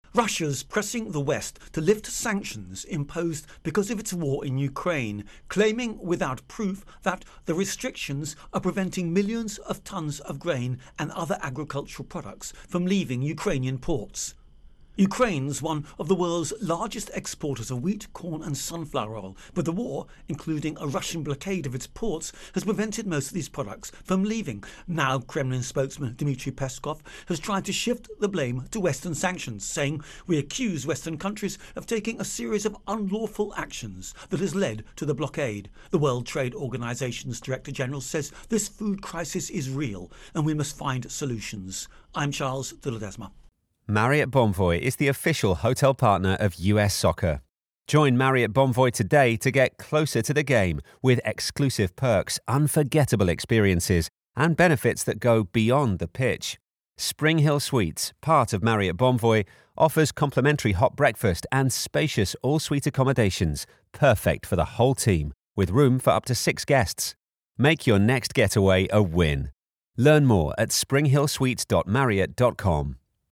Russia Ukraine War Sanctions Intro and Voicer